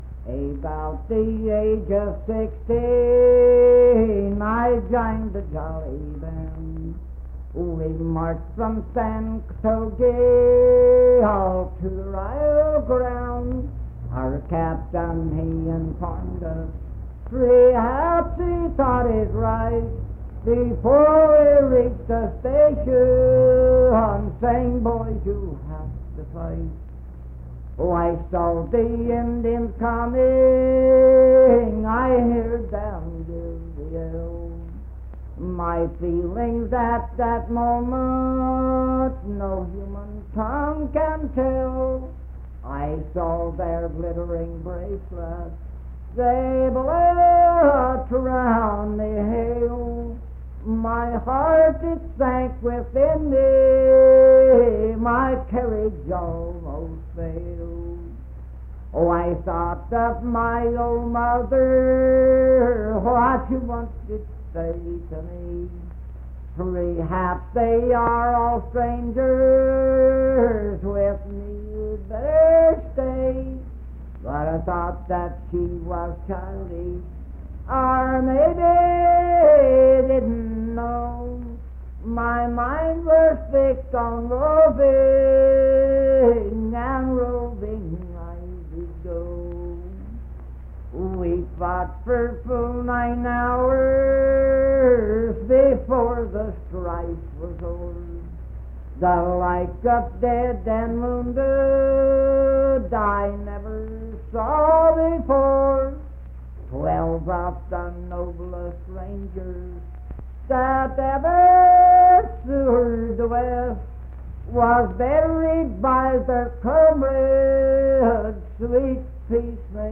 Unaccompanied vocal music performance
Voice (sung)
Spencer (W. Va.), Roane County (W. Va.)